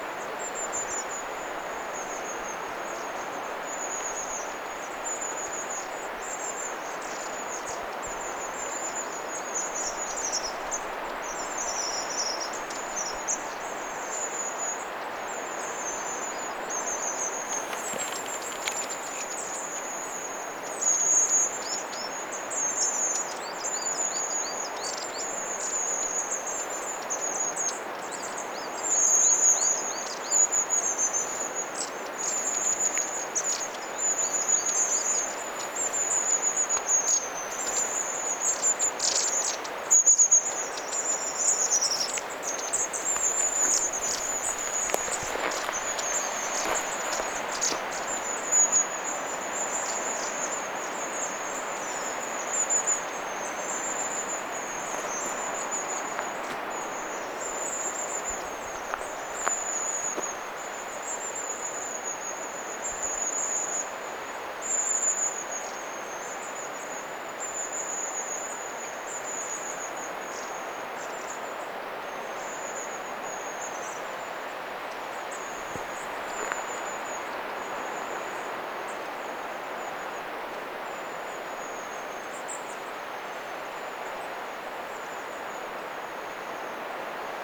pyrstötiaisia saaressa, 2
pyrstotiaisia_saaressa2.mp3